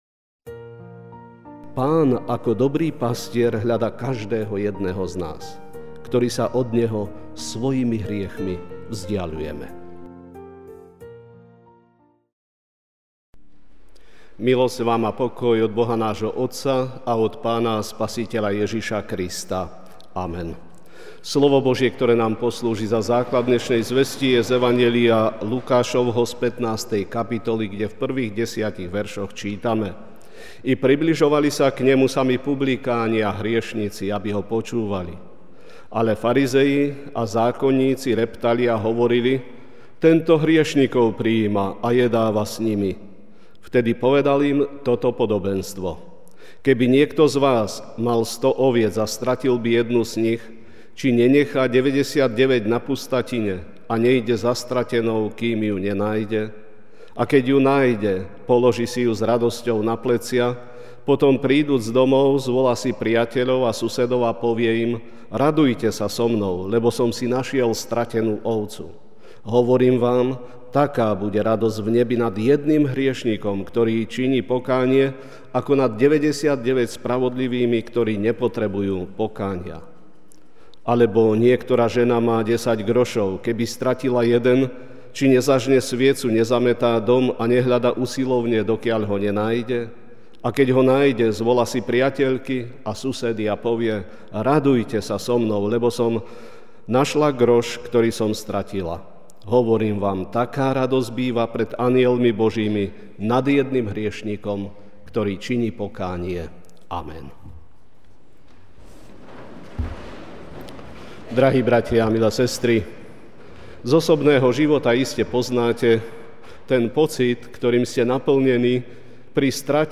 Ranná kázeň: Pán ako dobrý pastier hľadá každého jedného z nás (Lukáš 15, 1-10) I približovali sa k Nemu samí publikáni a hriešnici, aby Ho počúvali.